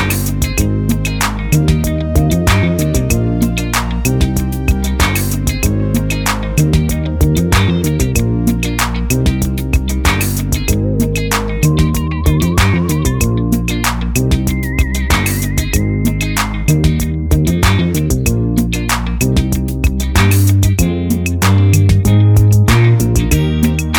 Soul / Motown